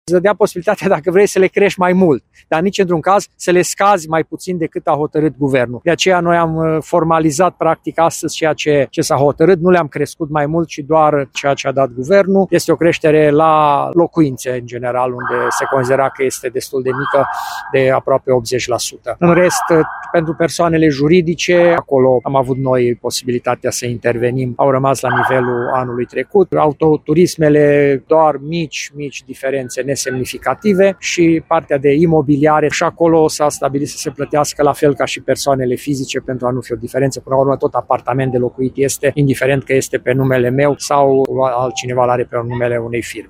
Primarul Călin Bibarț a declarat că hotărârea a fost întocmită strict pe baza cadrului legal în vigoare și a avertizat că neadoptarea ei ar fi atras penalizări financiare pentru municipiu.